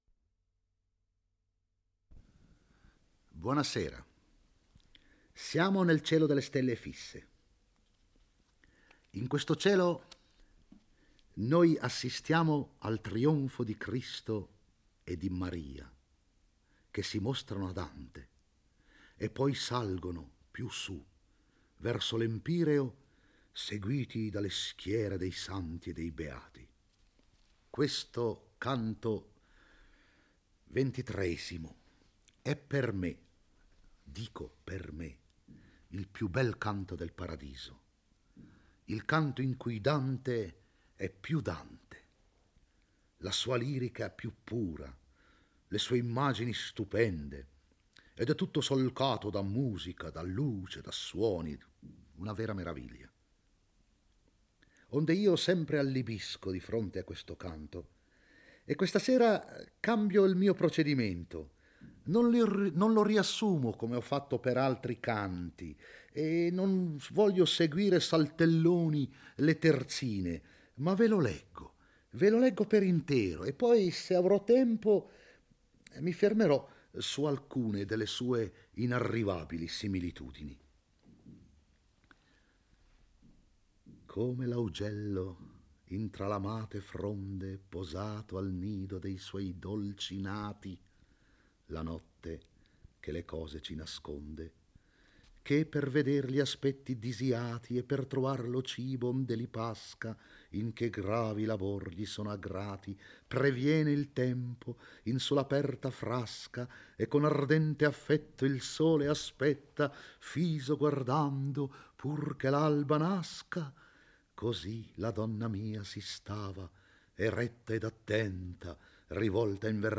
legge e commenta il XXIII canto del Paradiso. IIl poeta vede un Sole che illumina migliaia di splendori e attraverso cui traspare la figura di Cristo.